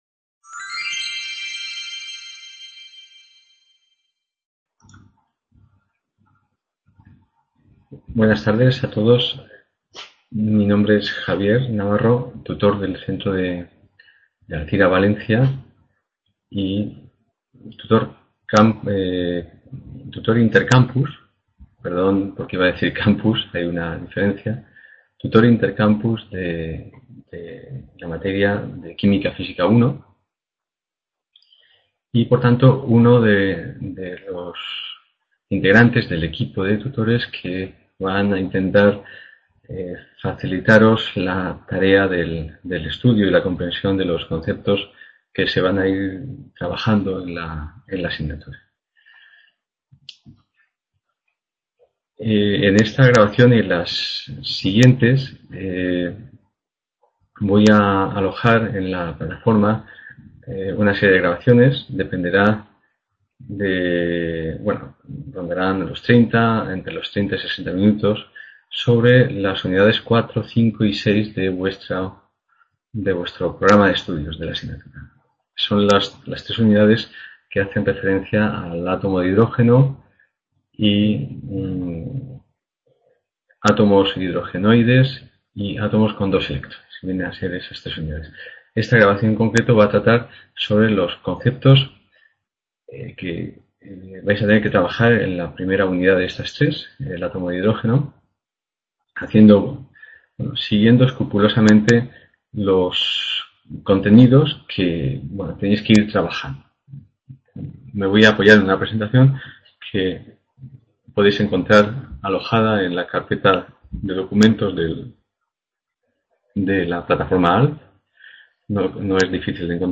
Aula de Química Física
Video Clase